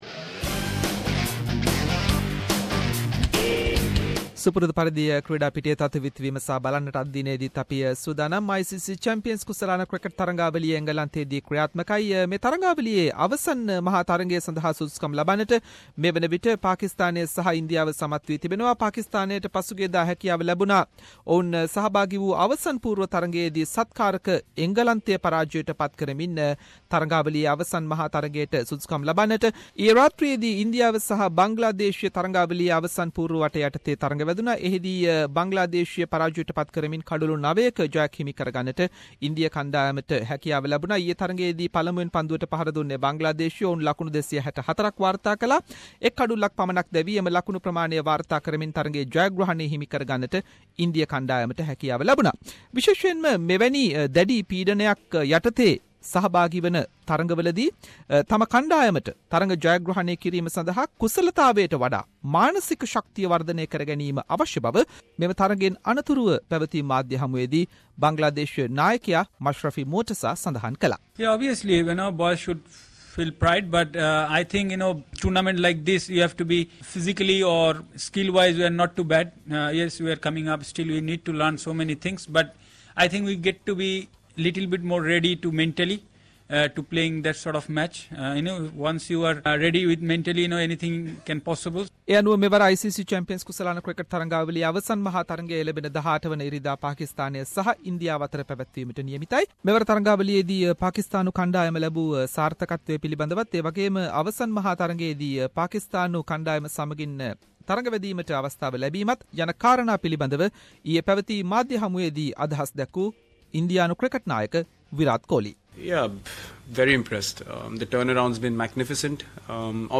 Weekly Sports wrap – India to take on Pakistan in ICC Champions Trophy 2017 final